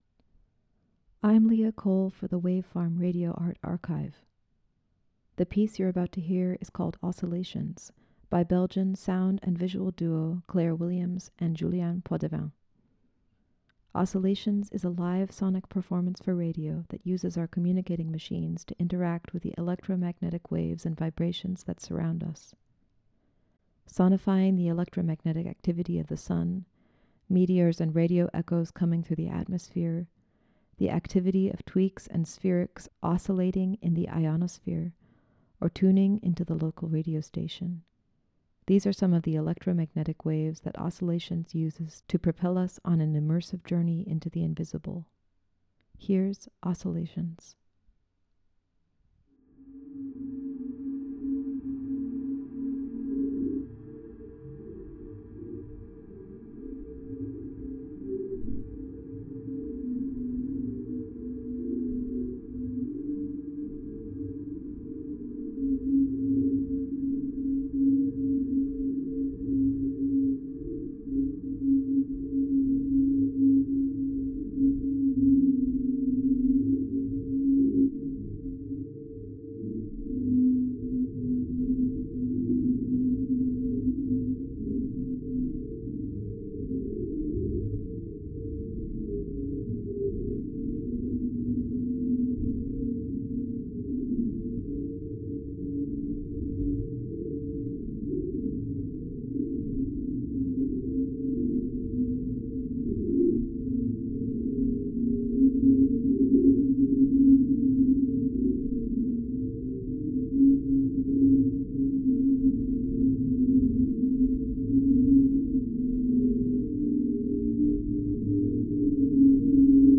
Oscillations is a live sonic performance for radio...
Sound is used to modulate space and time as machines, antennas, radios, microphones and body's to stream these ethereal vibrations taken from different locations on the earth and from our atmosphere in real time. Sonifying the electromagnetic activity of the sun, meteors and radio echoes coming through the atmosphere, the activity of tweaks and spherics oscillating in the ionosphere, or tuning in the local radio station: these are some of the electromagnetic waves that Oscillations uses to propel us on an immersive journey into the invisible.